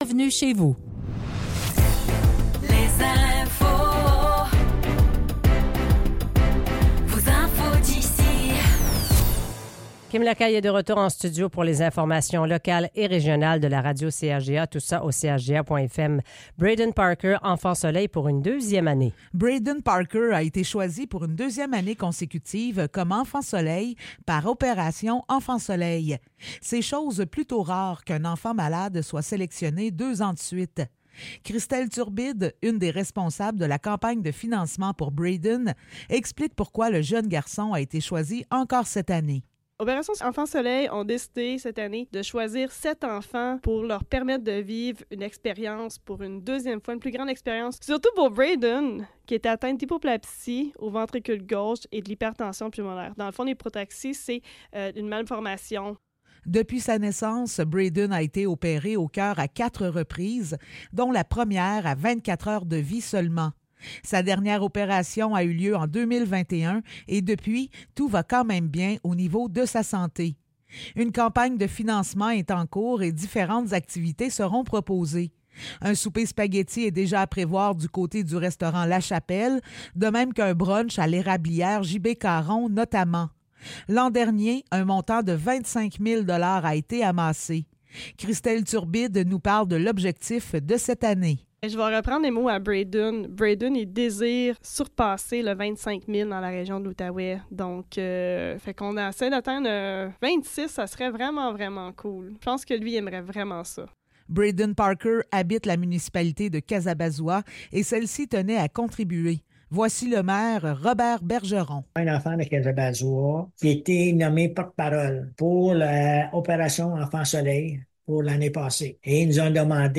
Nouvelles locales - 30 janvier 2024 - 8 h